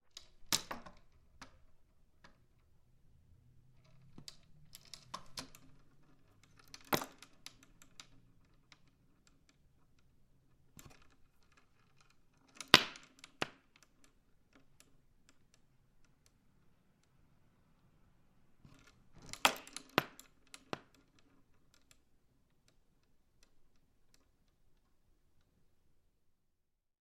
随机的 " 木头门 粗糙的公寓前门笔直的关闭艰难的捕捉吱吱作响的声音
描述：门木多节公寓前门笔关闭艰难抓住吱吱声rattle.flac
标签： 关闭 前面 木材 拨浪鼓 坚韧 公寓 粗糙
声道立体声